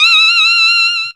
DOG WHISTLE.wav